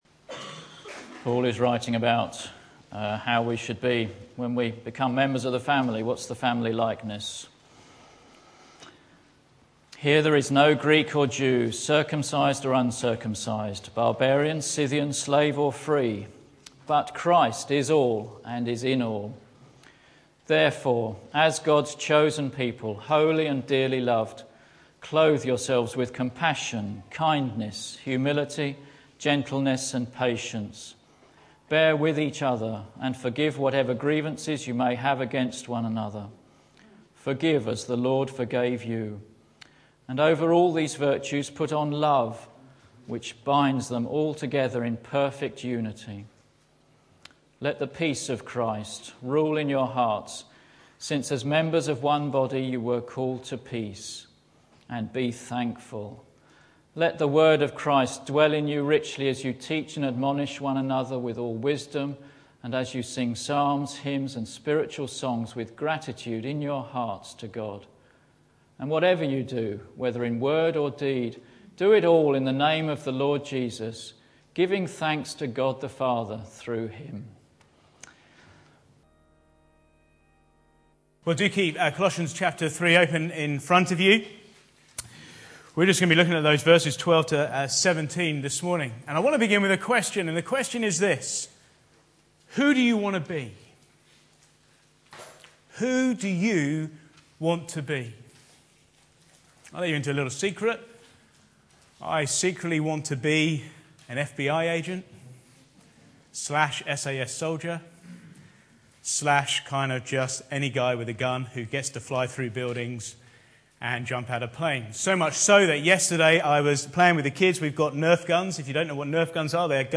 Back to Sermons New Clothes